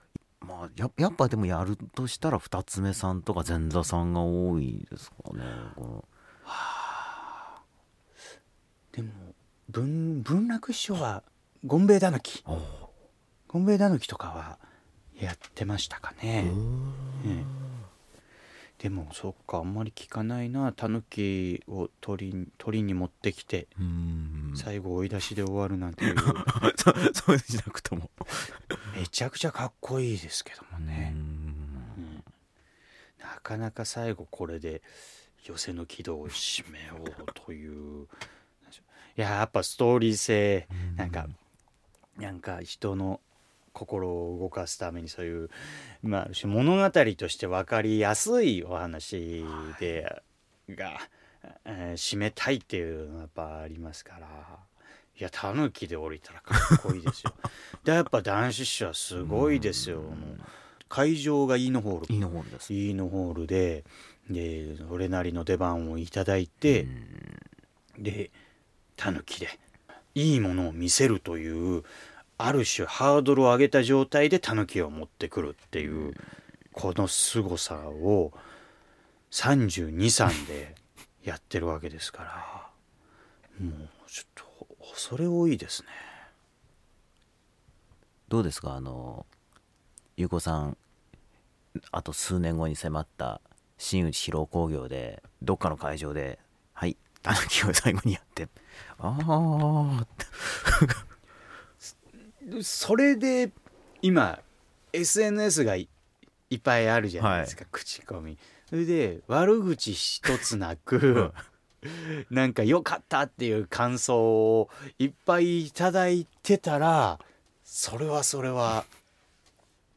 【調点】未公開トーク